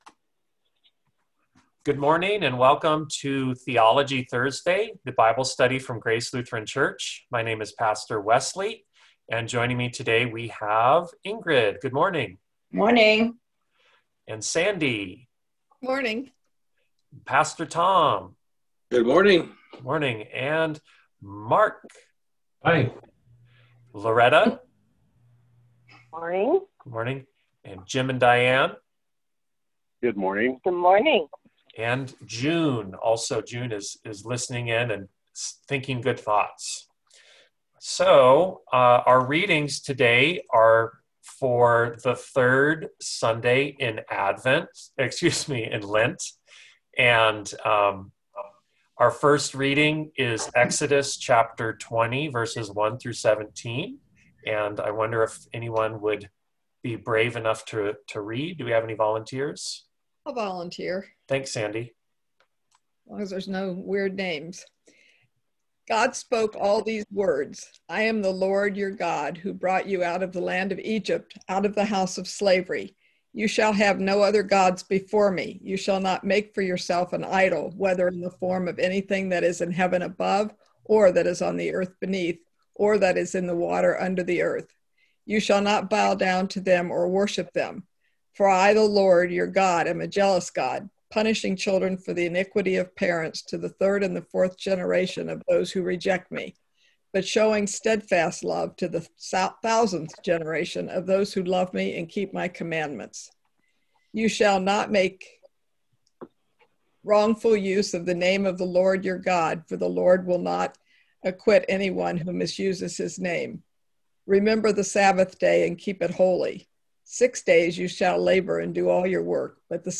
Theology Thursday Bible Study March 4, 2021 - Grace Lutheran Church & Preschool